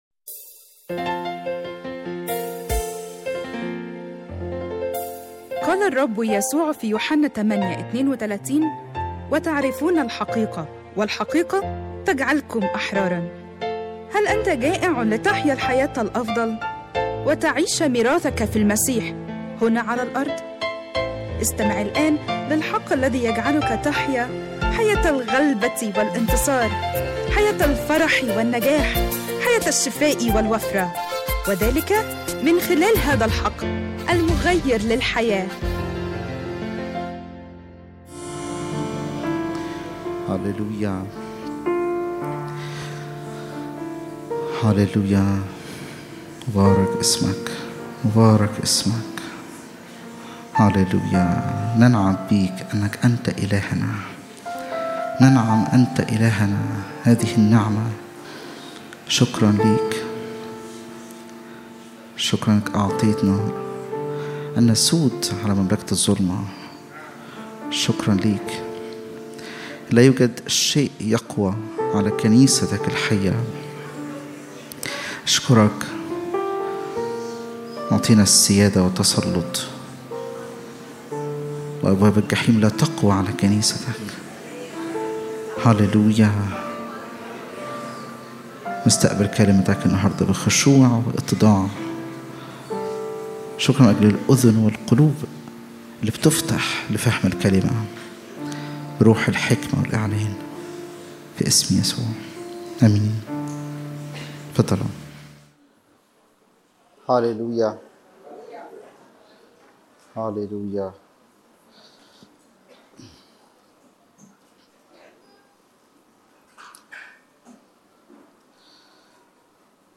اجتماع الثلاثاء
العظة